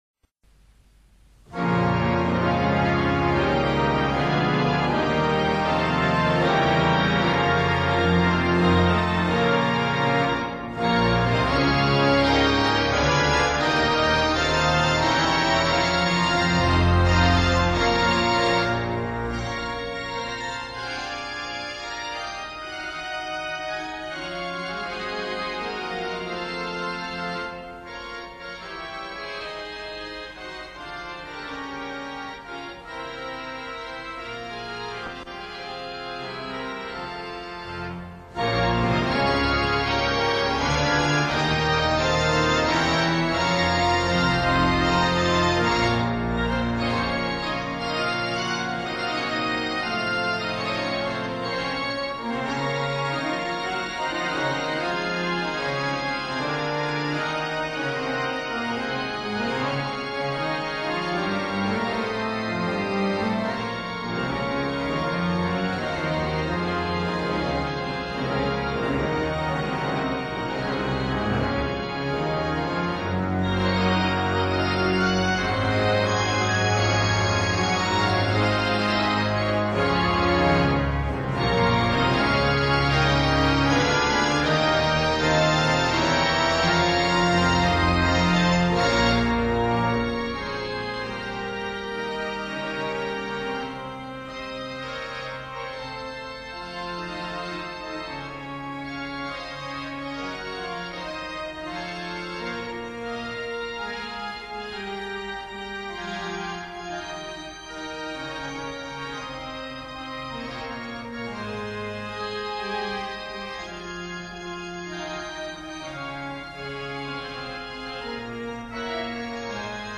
à la tribune du grand orgue de Notre-Dame de Paris
Fichier MP3 Léonce de Saint-Martin interprète la
Passacaille de Couperin (transcription de L. de Saint-Martin).
à Notre-Dame de Paris, vers 1954